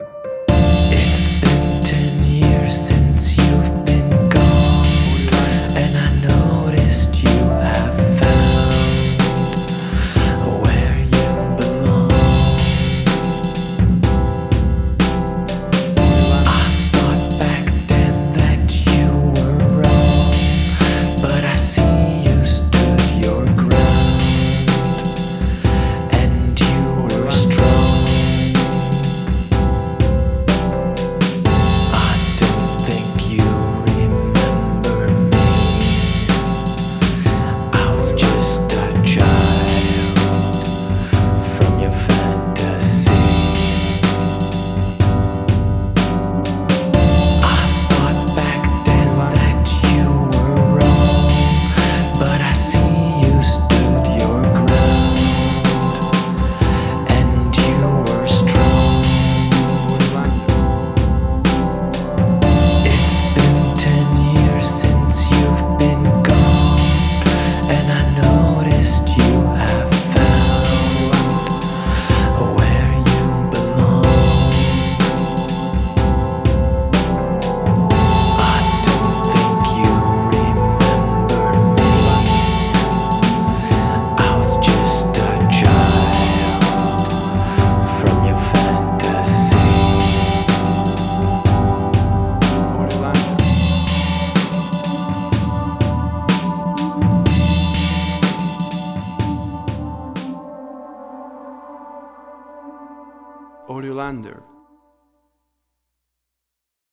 Alternative Songs with vocals
Tempo (BPM): 62